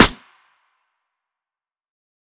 Snares
FS_SNR.wav